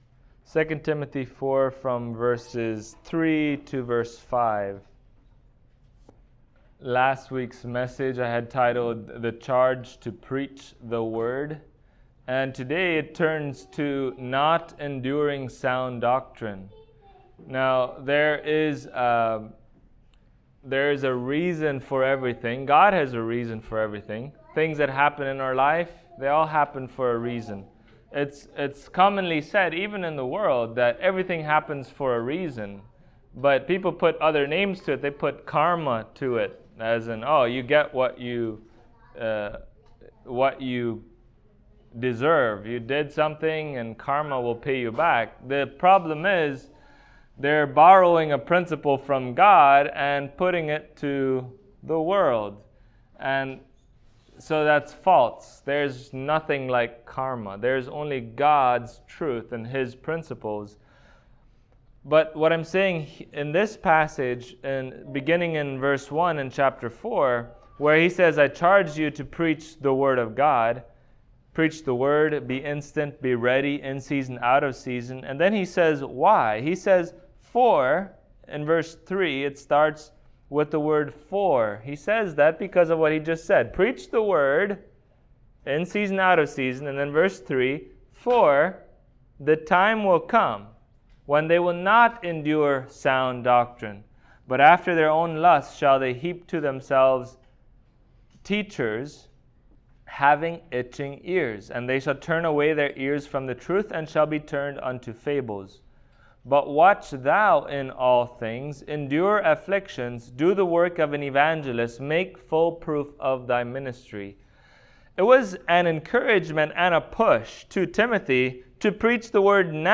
Service Type: Sunday Morning Topics: Doctrine , Hypocrisy